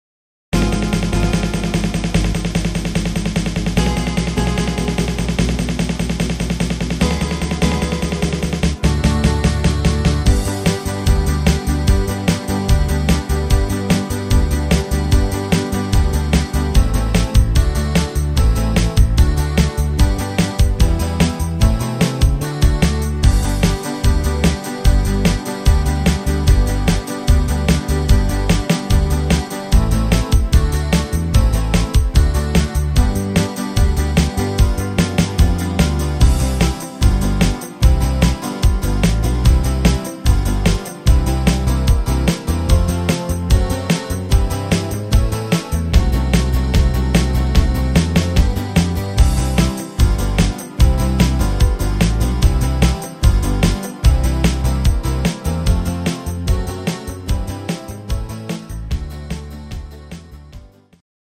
(instr. Gitarre)